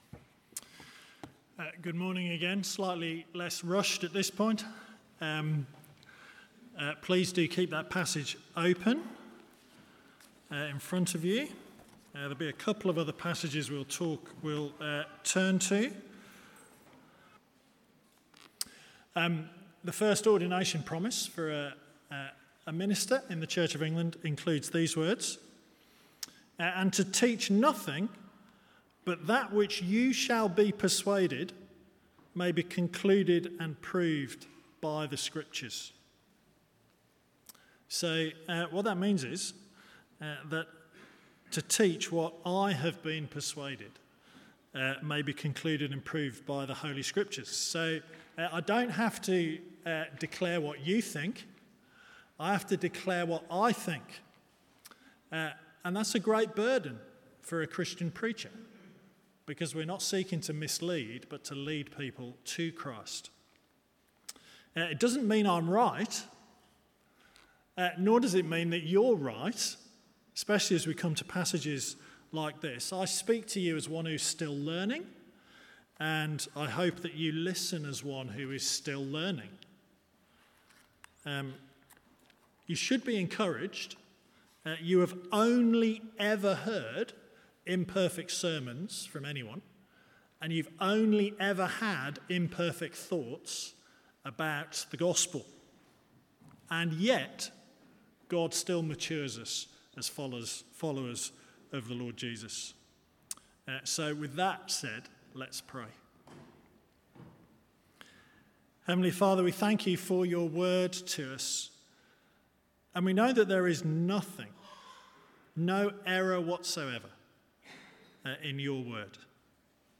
Sermons from Holy Trinity Church, Oswestry